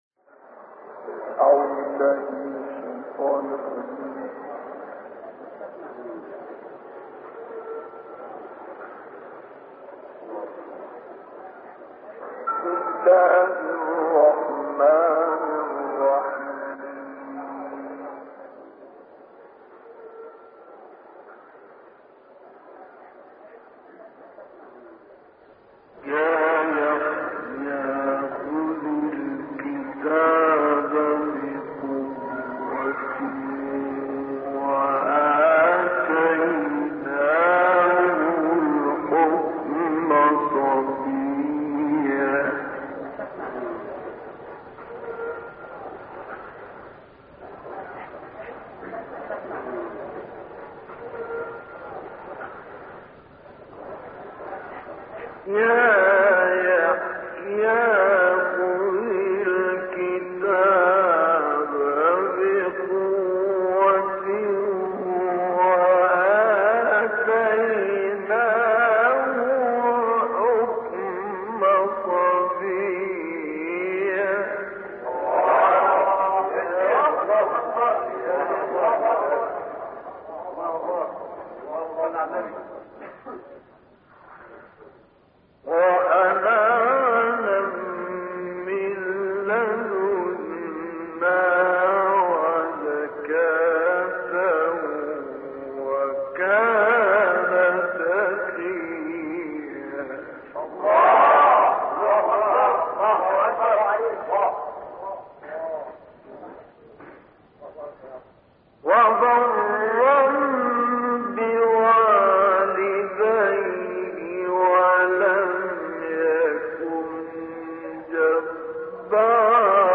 تلاوت قرآن كريم: سوره مریم آیه 12 تا 36، سوره الحاقه، نازعات، سوره شمس و سوره علق آیه 1 تا 5 با صدای استاد مصطفی اسماعیل
تلاوت سوره مریم آیه 12 تا 36، سوره الحاقه، نازعات، سوره شمس و سوره علق آیه 1 تا 5